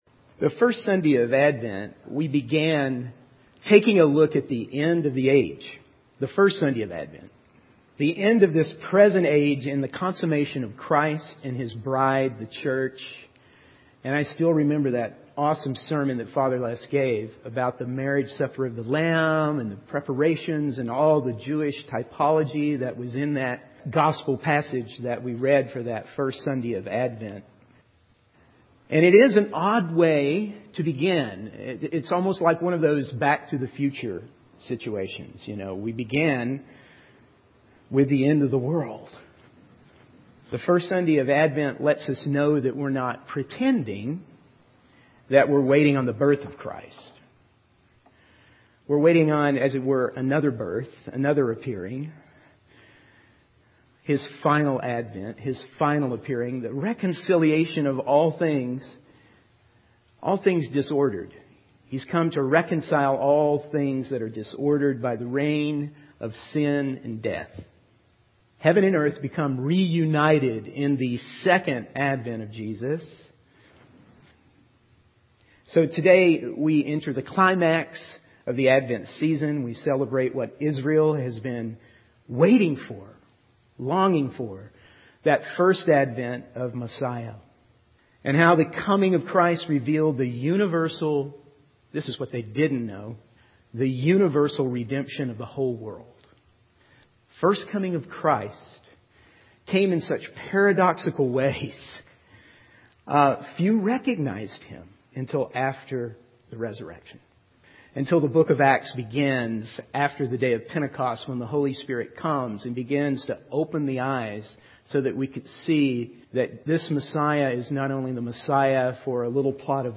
In this sermon, the speaker discusses the beginning of God's redemptive plan, which starts with a sex scandal involving Joseph and Mary.